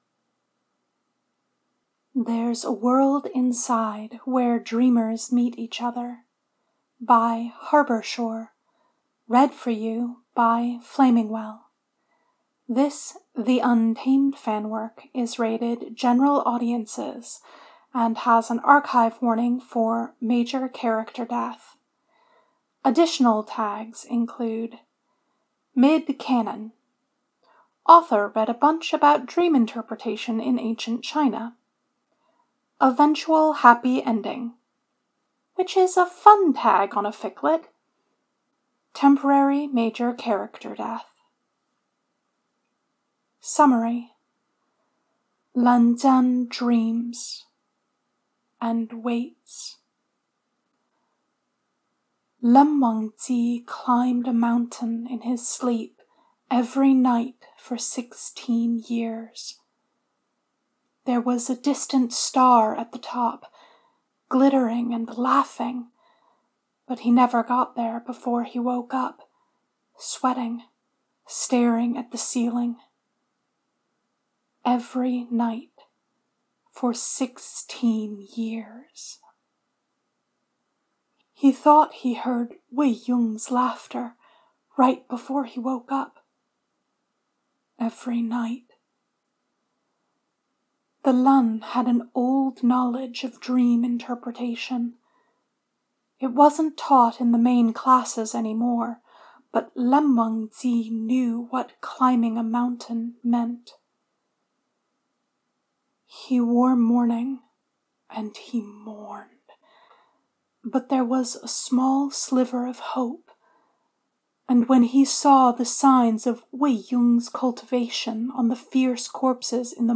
with music & sfx download mp3: here (r-click or press, and 'save link') [48 MB, 01:05:50]